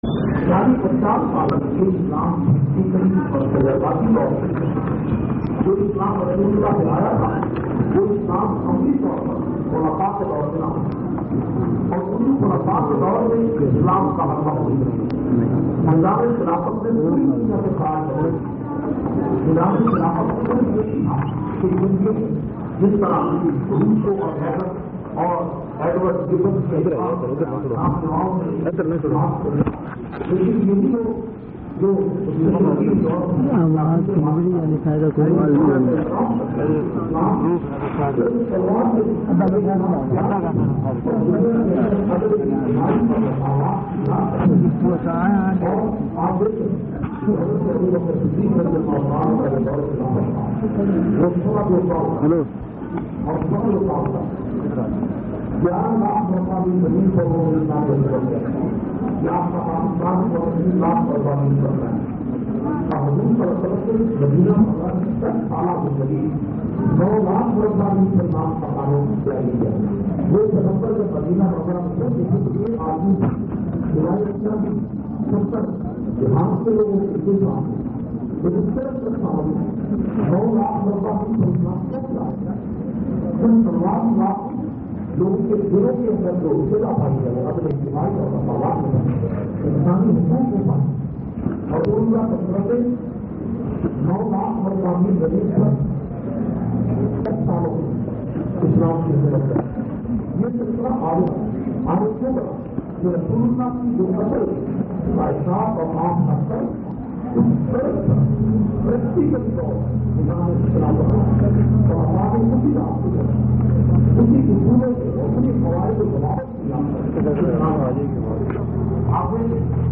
550- Wokala Se Khittab Khaniwal District Bar Association.mp3